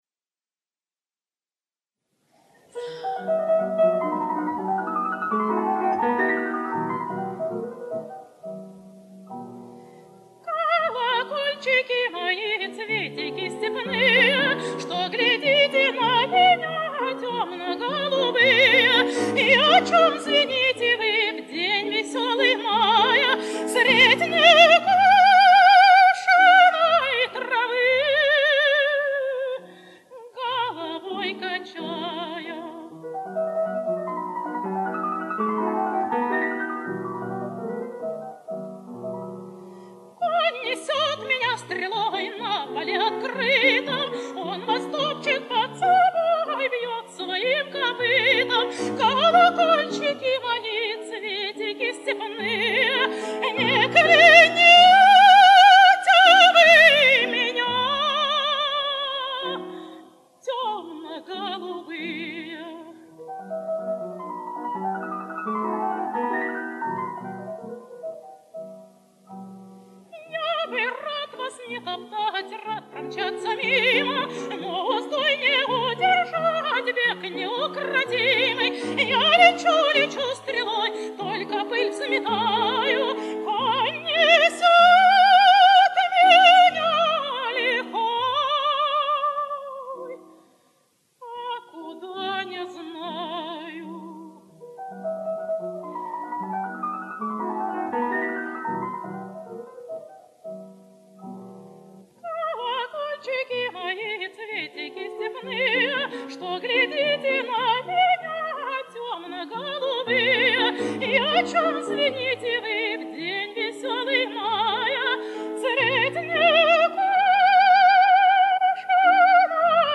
Во Всесоюзный Радикомитет Казанцеву приняли по конкурсу на амплуа лирико-колоратурного сопрано. Более 20 лет звучал в эфире её нежный, лёгкий, кристально чистый голос.